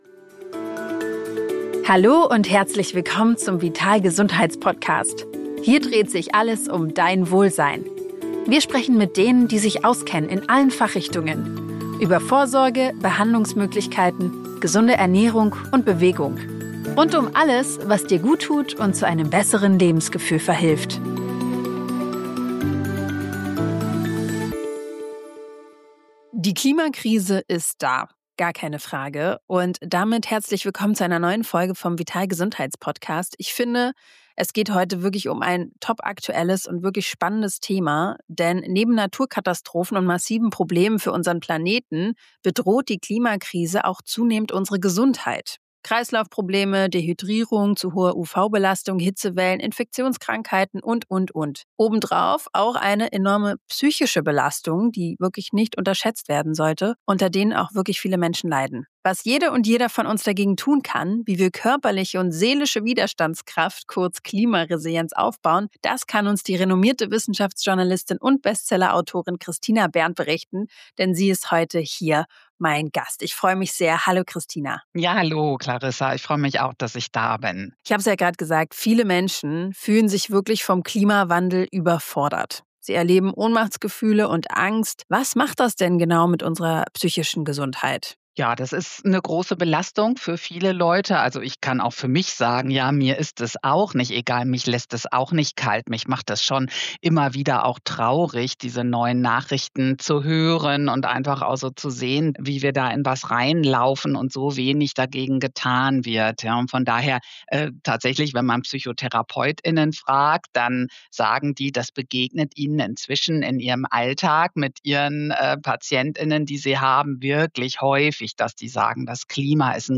1 Episodio # 25 Beatriz Rico: Vitalidad, Deporte y Superación Personal 💥 46:10 Play Pause 11h ago 46:10 Play Pause Main Kemudian Main Kemudian Senarai Suka Disukai 46:10 En este episodio de Mujer Vital Podcast, recibimos a Beatriz Rico, una mujer inspiradora llena de energía y autenticidad.